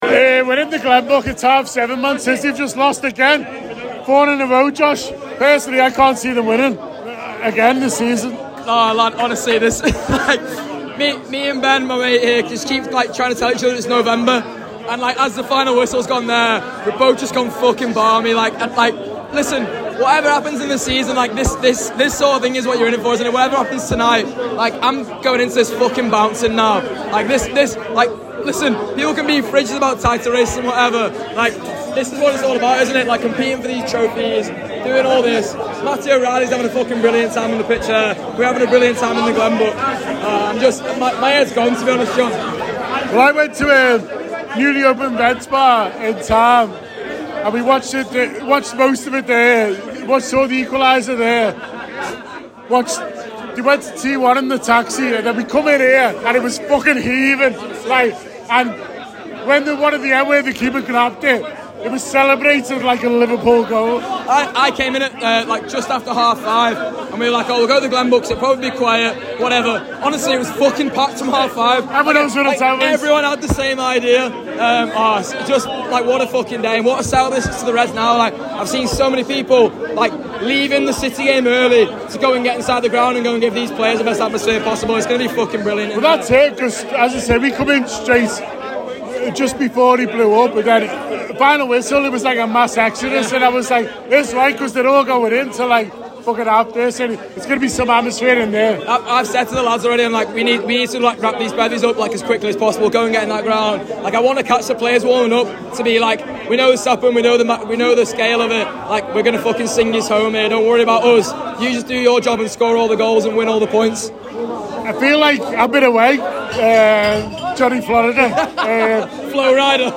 The Anfield Wrap’s Match Day Diary speaks to supporters at Anfield on the day Liverpool faced Aston Villa at Anfield.